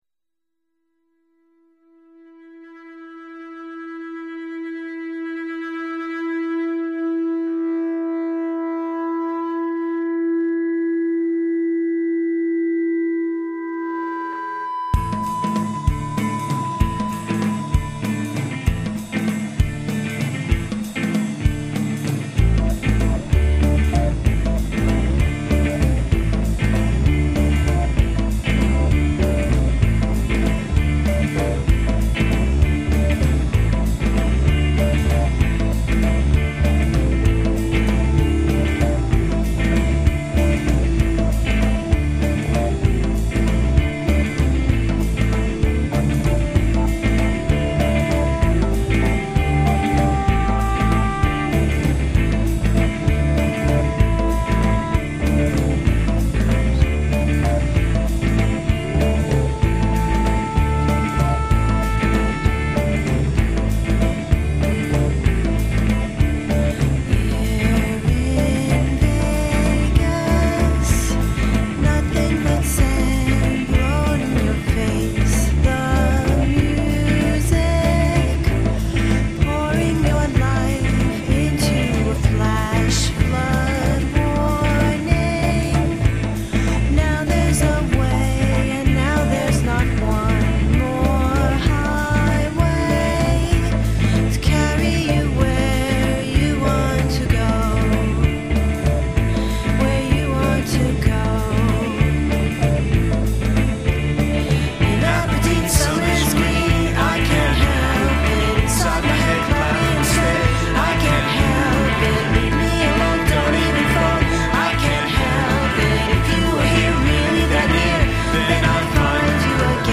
All songs are near-CD quality mp3 (96 kbps - 44 khz).